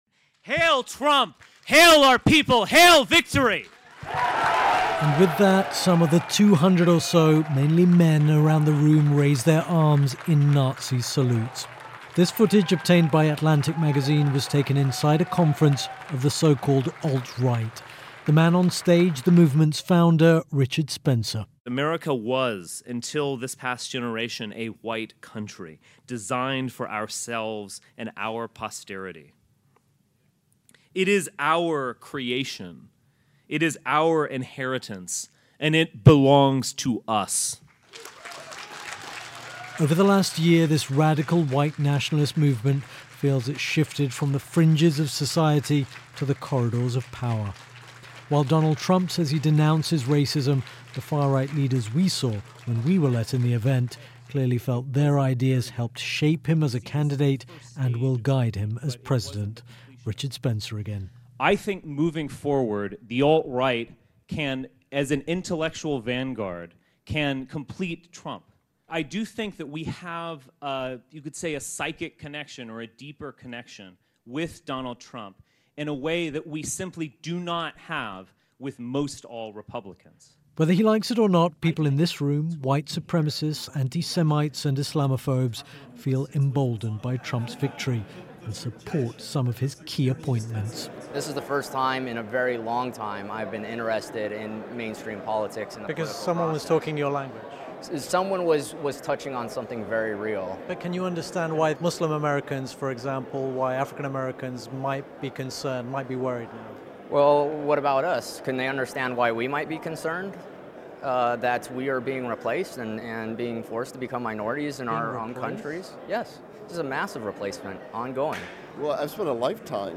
Far-right gathering celebrating Trump victory